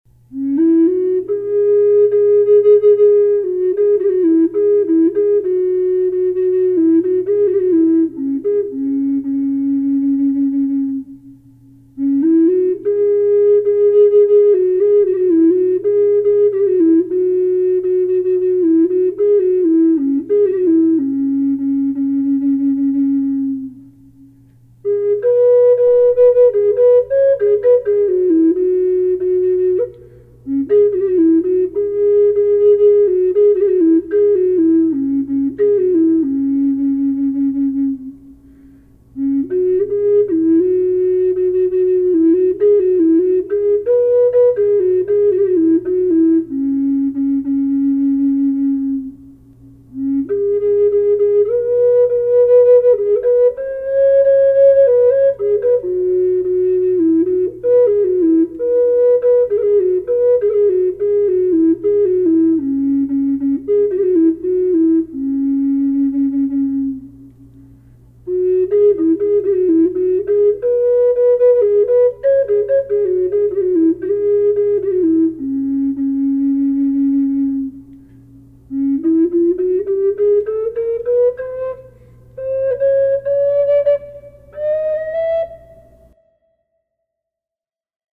Aspen low C# minor
with a light reverb